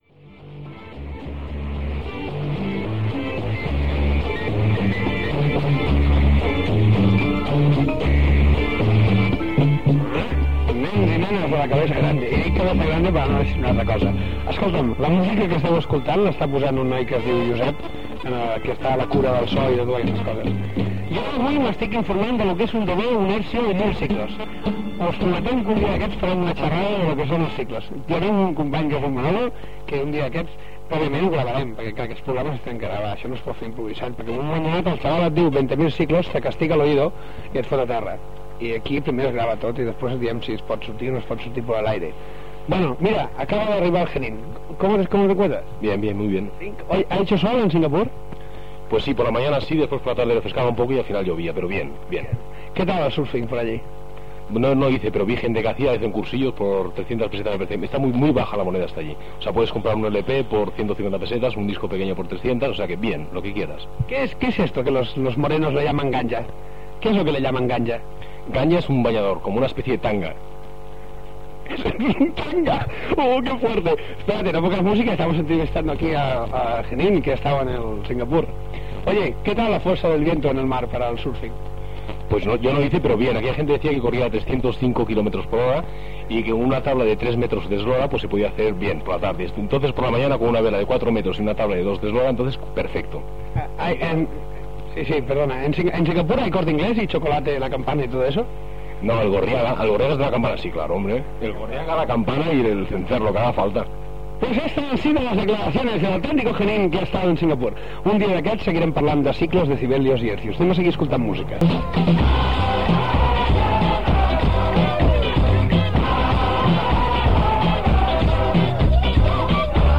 Entreteniment
FM
Emissora lliure del barri del Poble-sec.